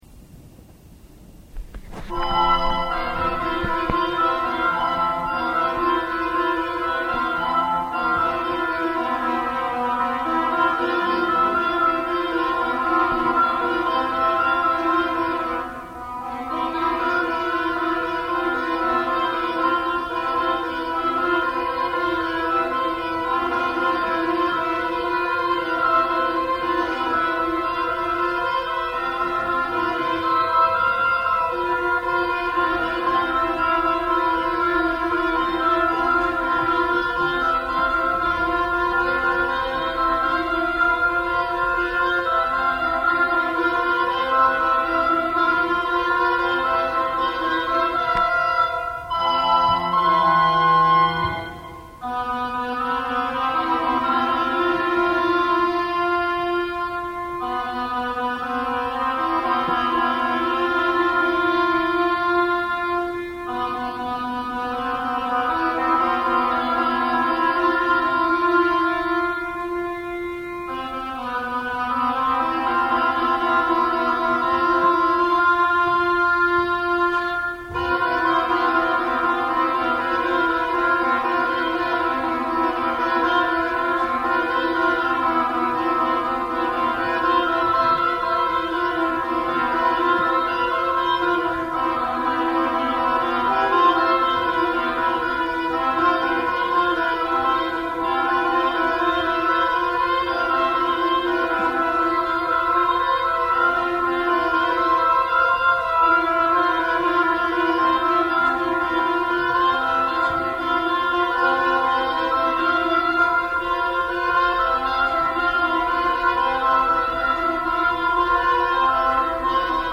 Российская премьера